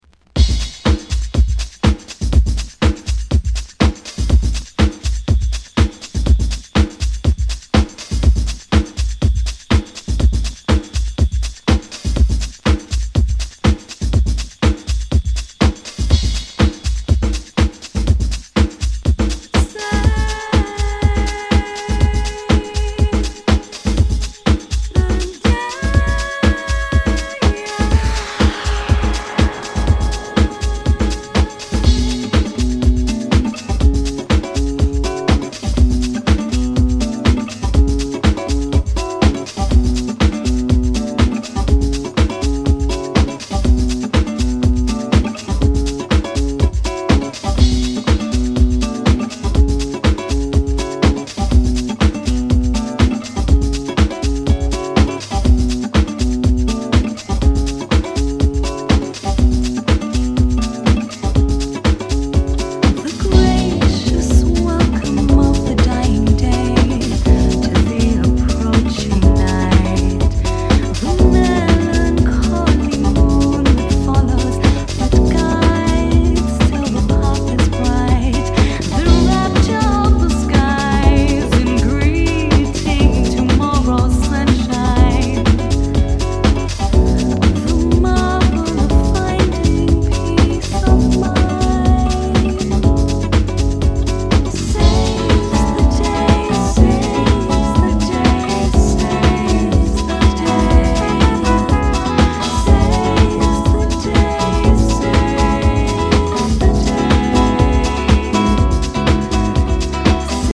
クラブ、ダンス